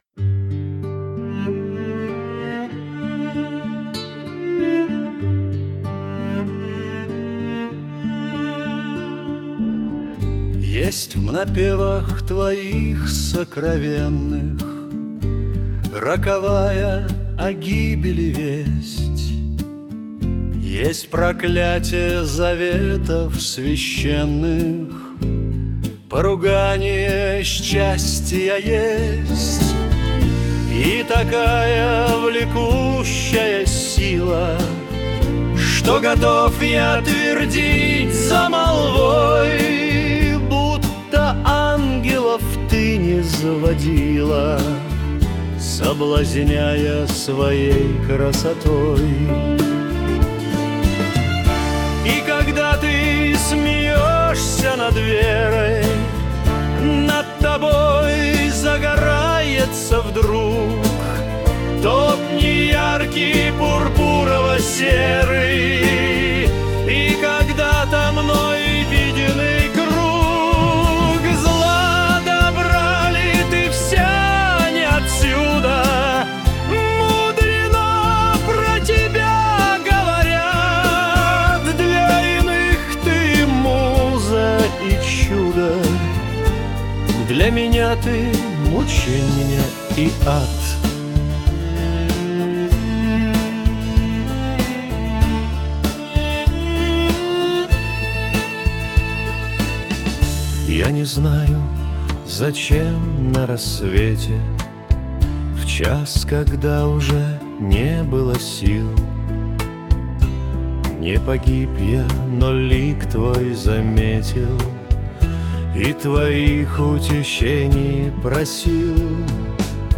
• 6: Рок